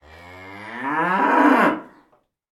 SND_cow_rnd_02.ogg